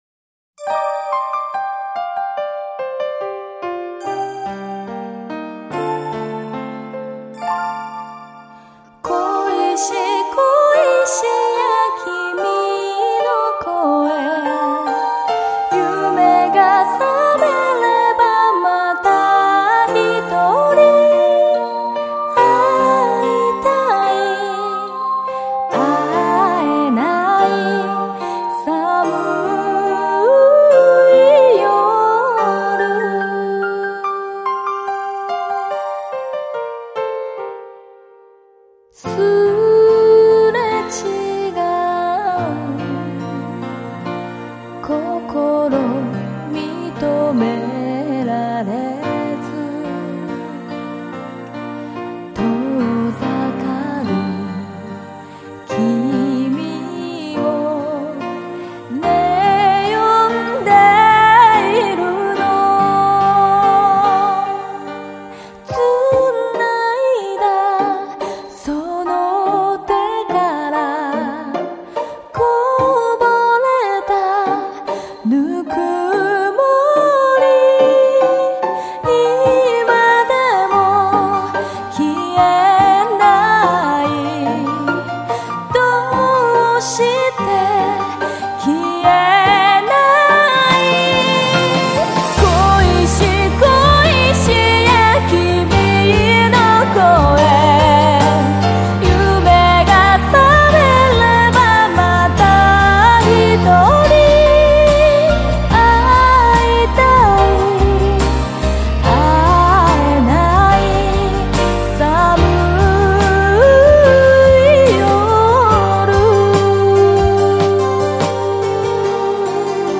---日本新秀岛歌歌手，被誉为“奄美大岛精灵寄宿之声”。
她的声音很宽，真假声完全自然的转换，没有拖拉之感。